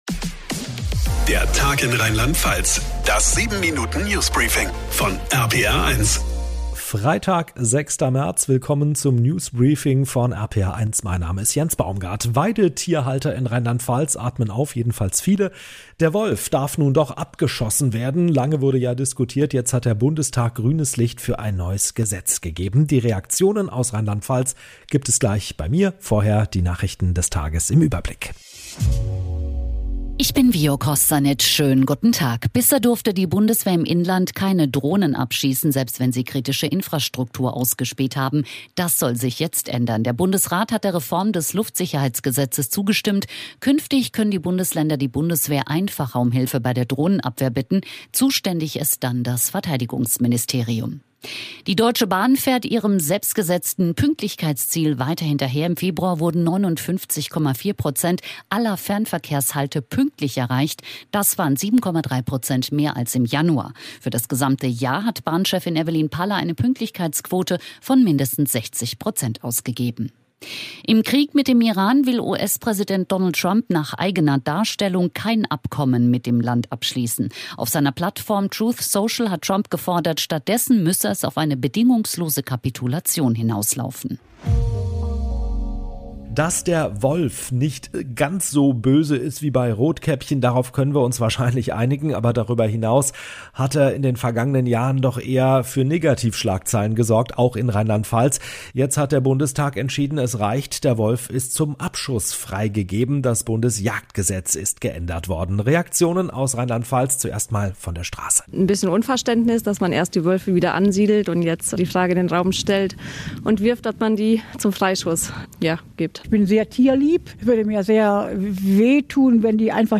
Das 7-Minuten News Briefing von RPR1.